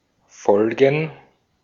Ääntäminen
Ääntäminen Tuntematon aksentti: IPA: /ˈfɔlɡŋ̩/ IPA: /ˈfɔlɡən/ Haettu sana löytyi näillä lähdekielillä: saksa Käännöksiä ei löytynyt valitulle kohdekielelle.